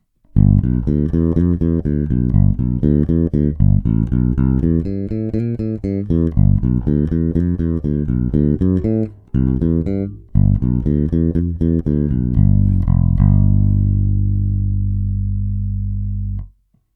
Posuďte (použity jsou struny Thomastik JR344 Jazz Rounds 43 - 89):
Samotný BC4CBC u kobylky
První dojmy: BC4CBC je průraznější a hlasitější než MK1, basa více vrčí, nějaká revoluce se sice na první poslech nekoná, ale na aparátu to znát je a jsem si celkem jist, že to pomůže prosazení zvuku i v hlučné kytarové kapele, s čímž jsem měl doteď jisté potíže řešené vytažením nižších středů na ekvalizéru.